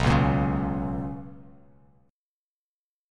BM7sus2 chord